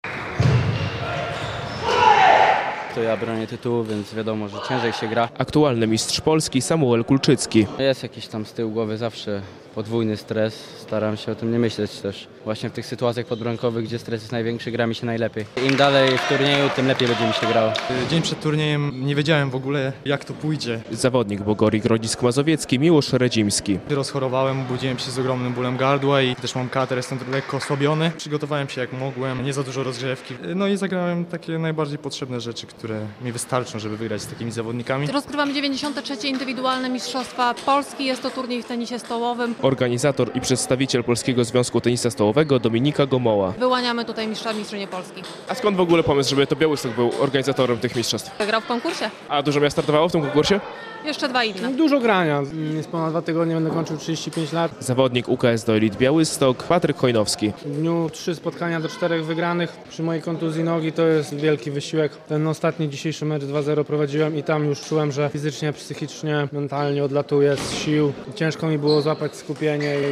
Wiadomości - Najlepsi tenisiści stołowi przyjechali do Białegostoku